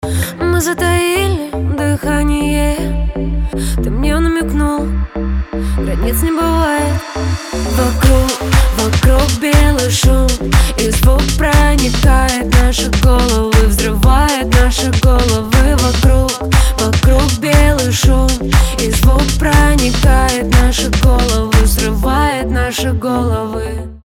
поп
чувственные
клубнячок